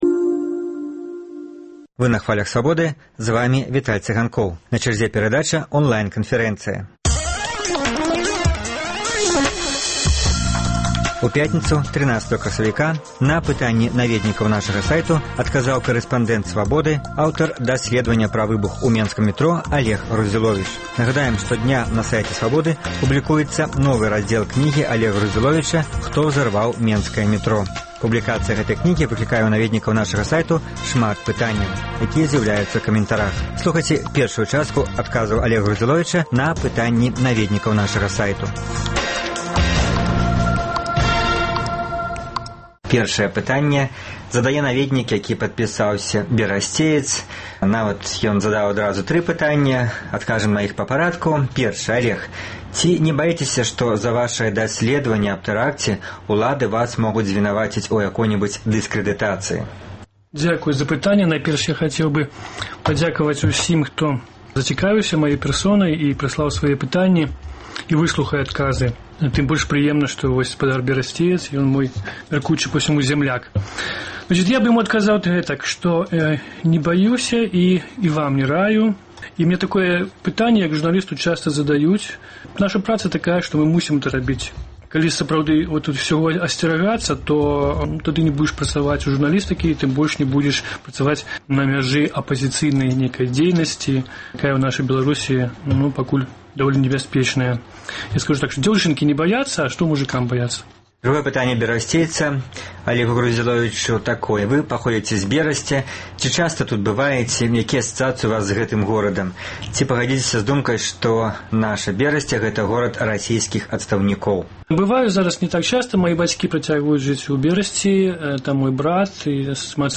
Радыёварыянт онлайн-канфэрэнцыі з карэспандэнтам «Свабоды»